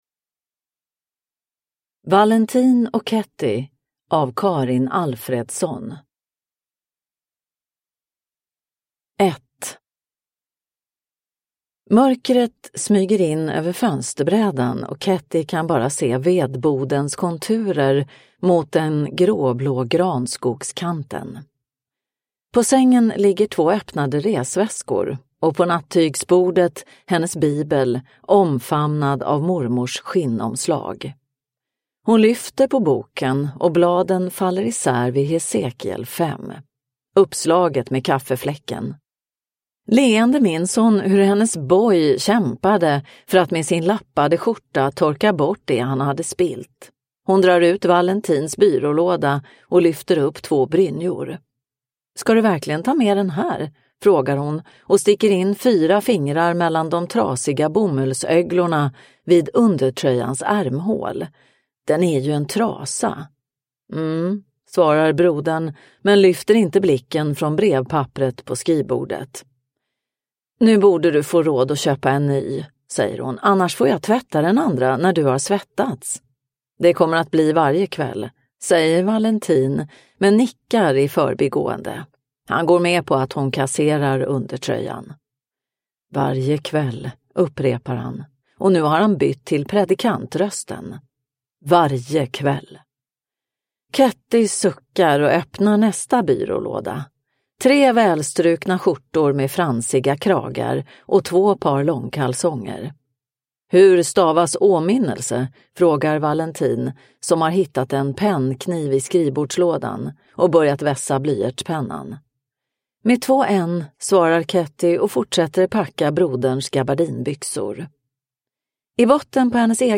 Valentin och Ketty – Ljudbok – Laddas ner